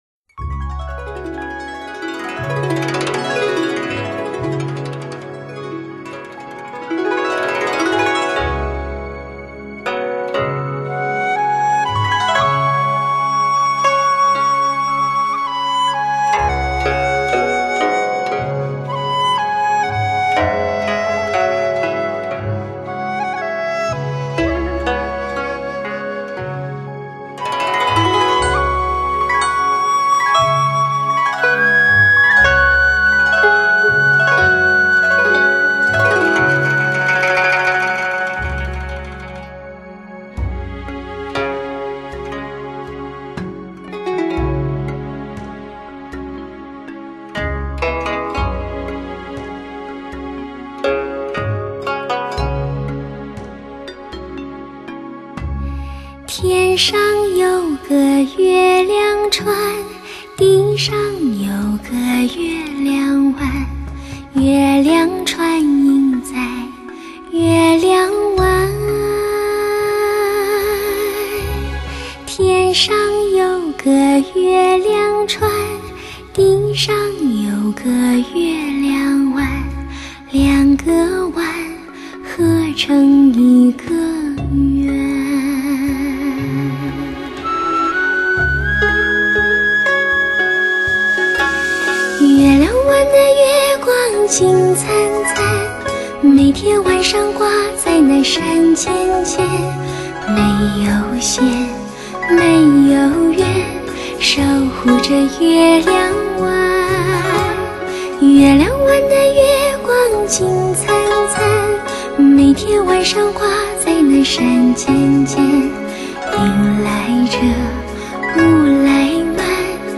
发烧女声
独具传统中国古文化格调，唱腔婉转流畅表现细腻动人，给你高水准艺术体验